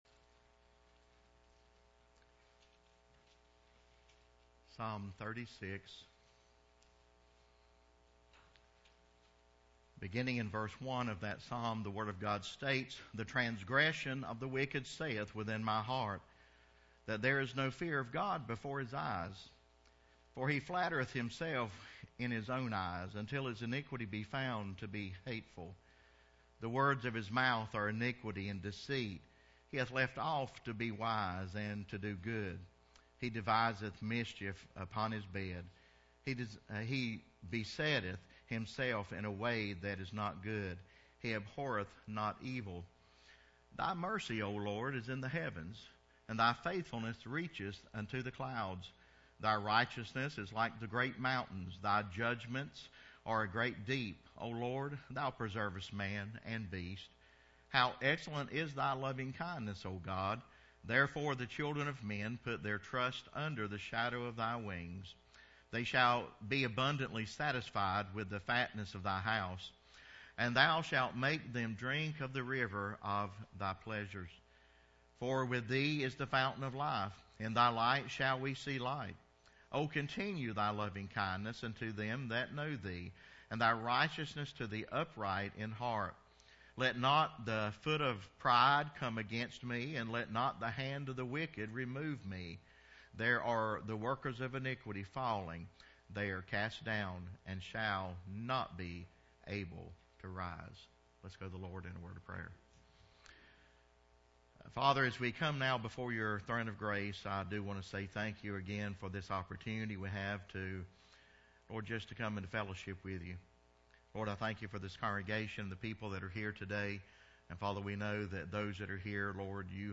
September 8, 2024 Seven Sad Steps of Wickedness Passage: Ps. 36:14 Service Type: Sunday Sermon Seven Sad Steps of Wickedness Text: Ps. 36:1-4 Intro: What is wrong with our country?